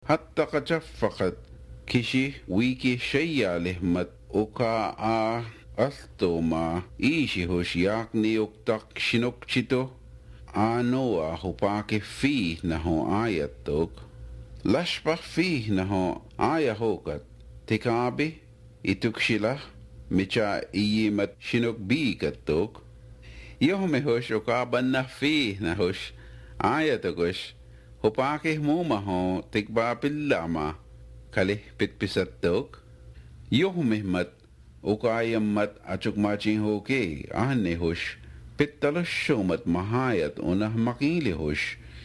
It’s clearly either a tonal or a pitch accent with two basic levels. 2.